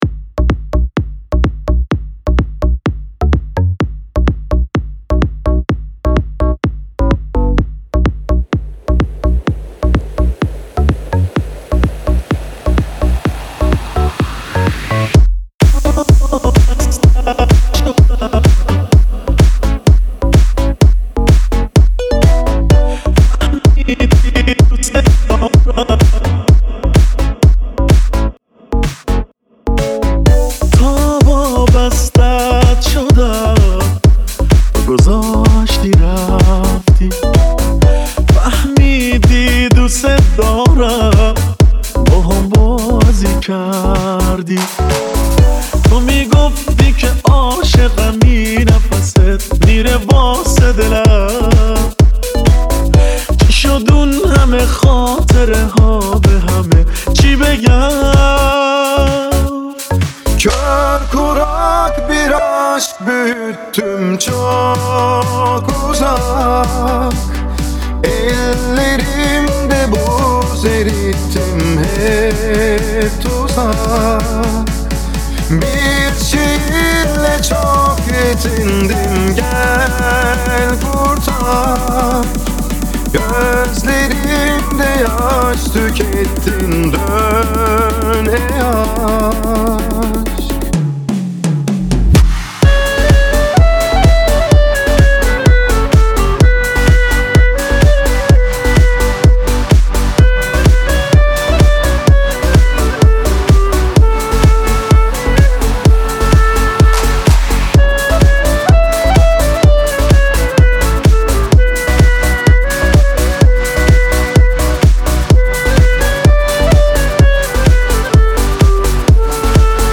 دانلود آهنگ ترکیبی ترکی فارسی غمگین